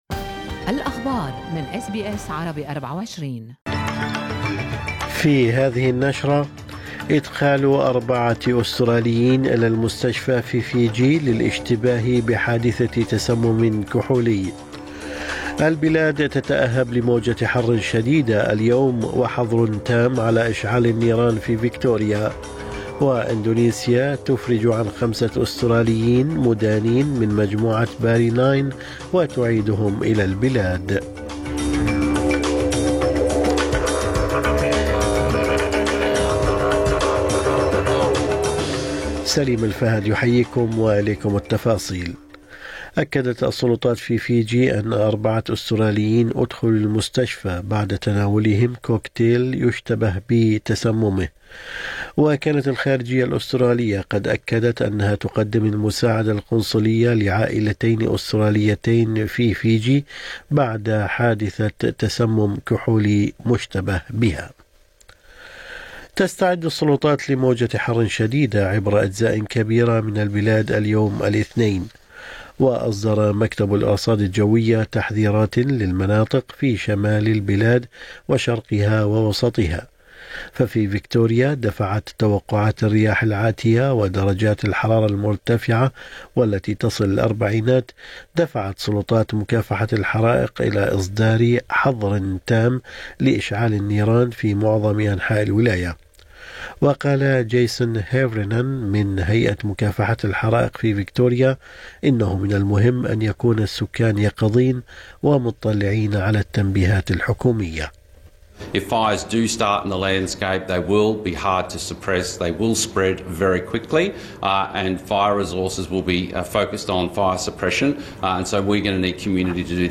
نشرة أخبار الصباح 16/12/2024